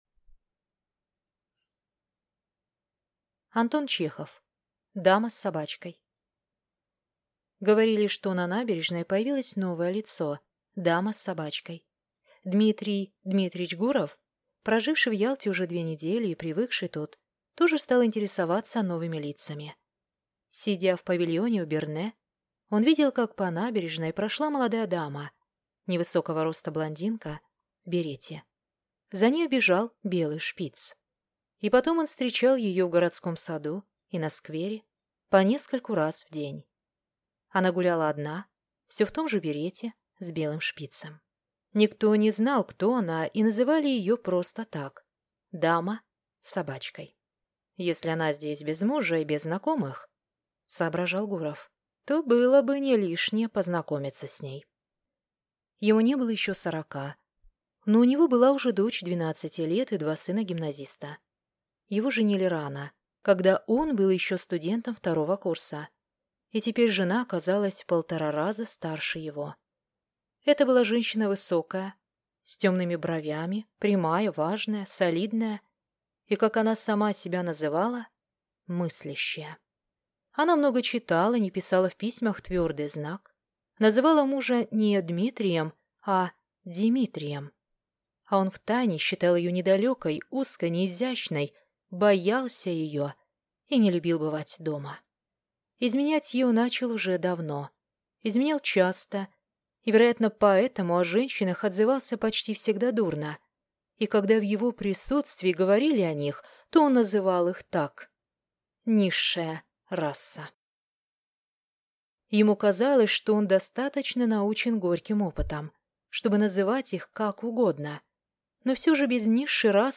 Аудиокнига Дама с собачкой | Библиотека аудиокниг